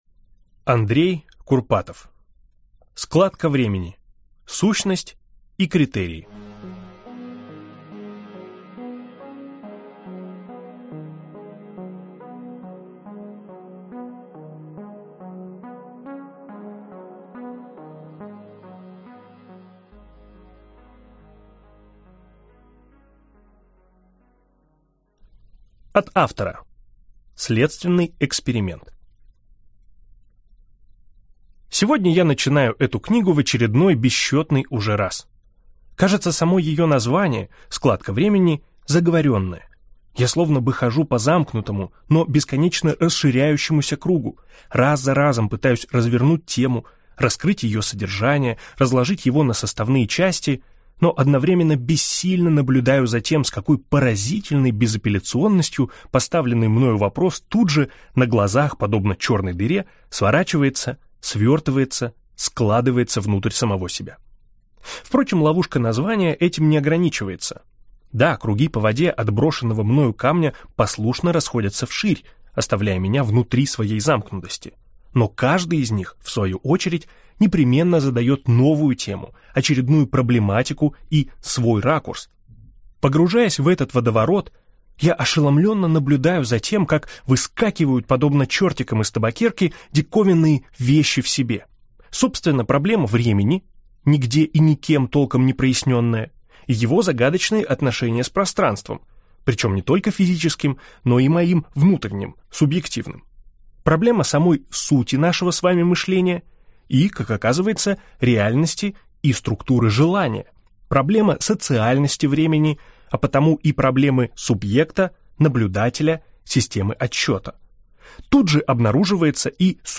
Аудиокнига Складка времени. Сущность и критерии | Библиотека аудиокниг